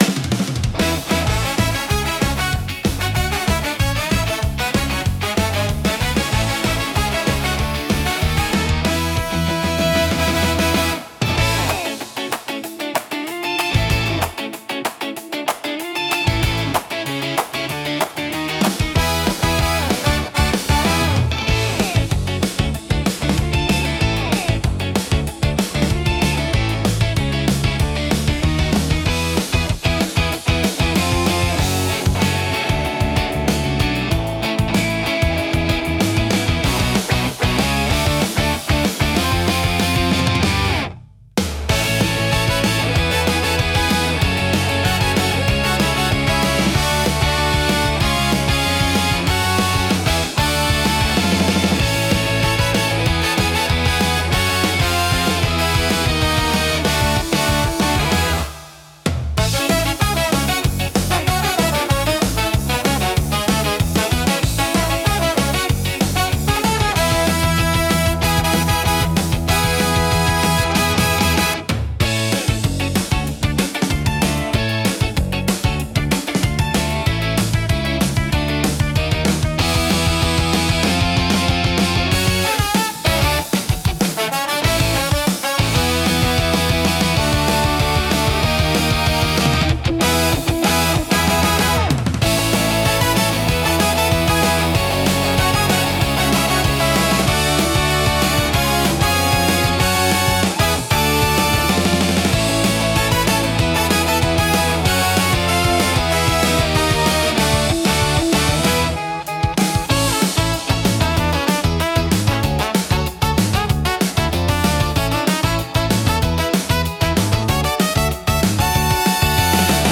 元気で勢いのあるサウンドが聴く人の興奮を高め、活気溢れる空間を作り出します。